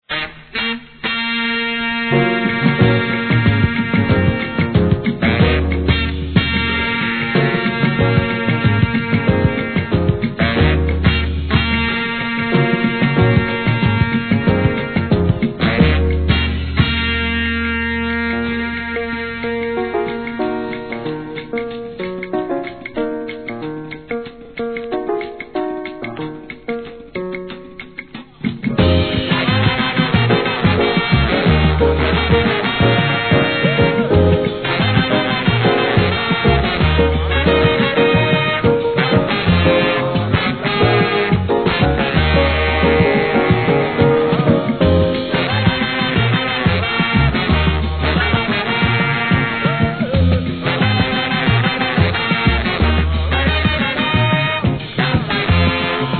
1. SOUL/FUNK/etc...
アフロパーカッションを下敷きにホーン＆アフロテイストなコーラス＆ヴォーカル、ギターなどが絡み合う究極のレアグルーヴ!